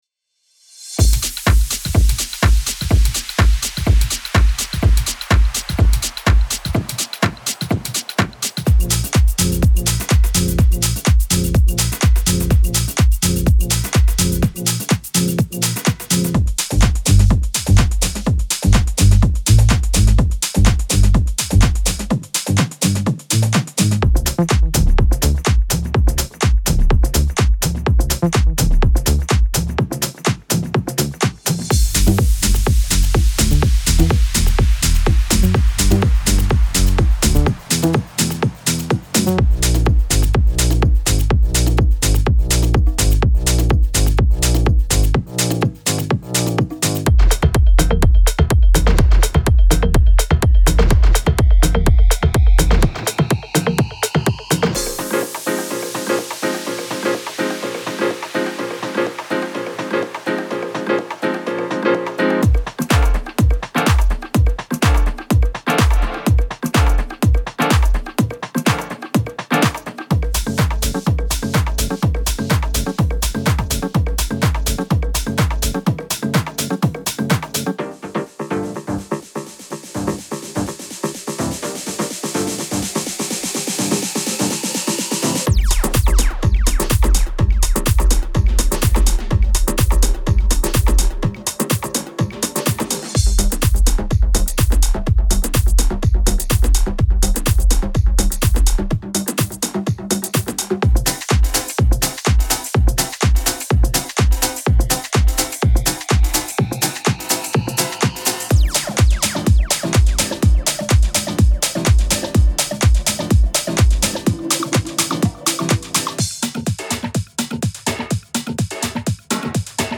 Type: Midi Samples
Deep House House Tech House